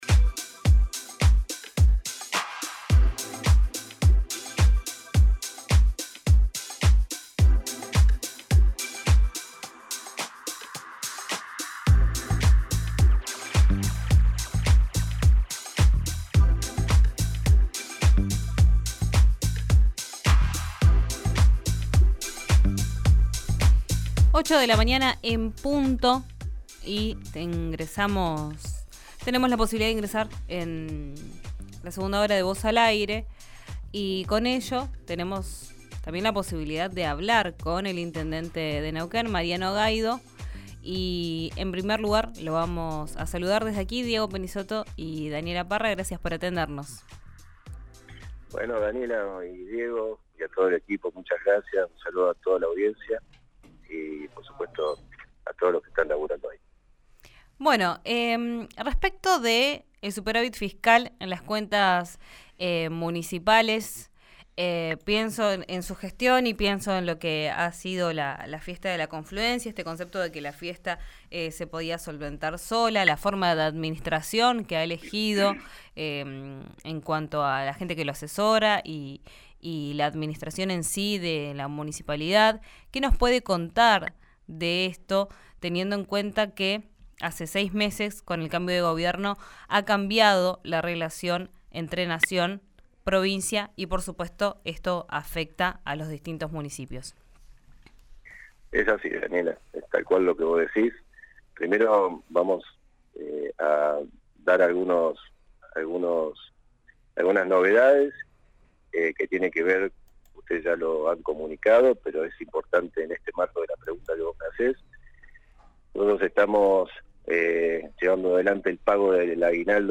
El intendente de Neuquén lo anunció en diálogo con RÍO NEGRO RADIO. El plan contemplaría el factor habitacional y capacitación laboral.
Escuchá al intendente de Neuquén, Mariano Gaido, en RÍO NEGRO RADIO